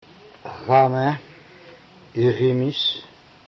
·x - podobne do polskiego ch językowego, wymawiane jednak bardziej „charcząco”.
·g - dźwięczna wersja poprzedniej głoski.
Przykłady wymowy ·h, ·x, ·g: